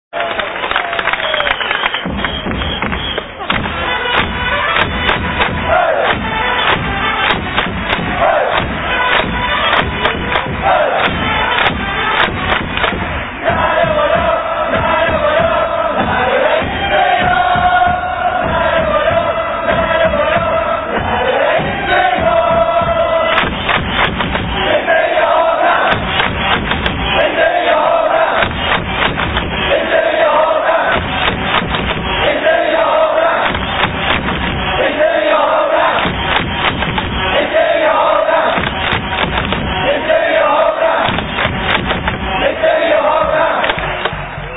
- 過去に使われていた応援歌 -